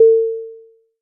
beep2.mp3